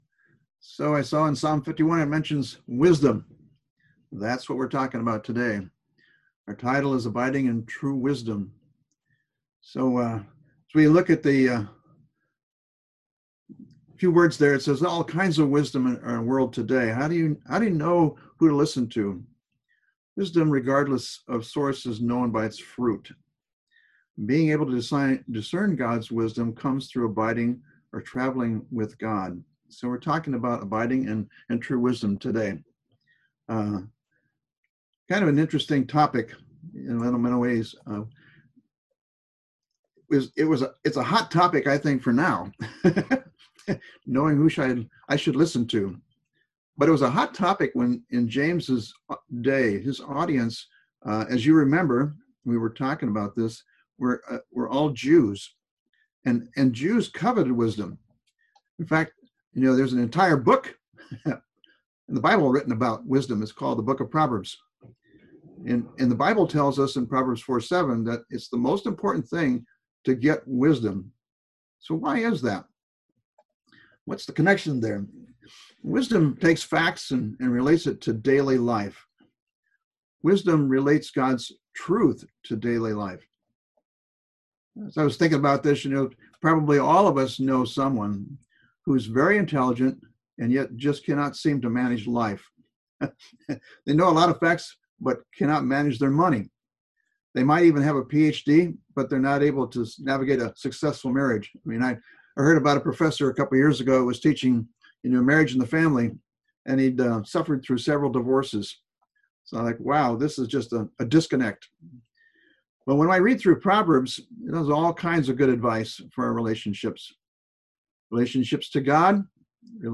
Apr 05, 2020 Abiding in True Wisdom (04.05.2020) MP3 PDF SUBSCRIBE on iTunes(Podcast) Notes Discussion Sermons in this Series Not all wisdom is created equal. True wisdom is known by its fruit.